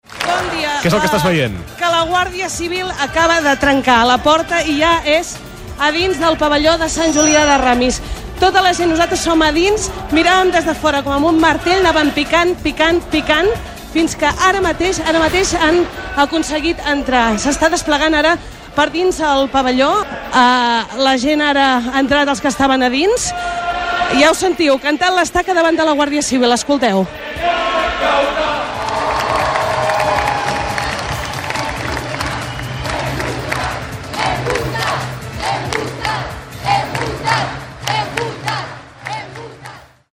Narració del moment en el qual la Guardia Civil entra al pavelló de Sant Julià de Ramis, col·legi on havia de votar el president de la Generalitat Carles Puigdemont en Referèndum d'Autodeterminació de Catalunya de l'1 d'octubre de 2017
Informatiu